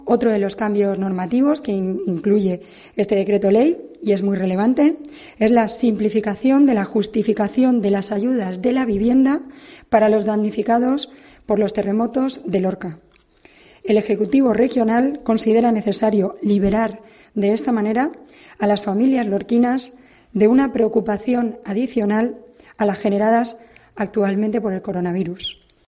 Ana Martínez Vidal, portavoz gobierno regional sobre terremotos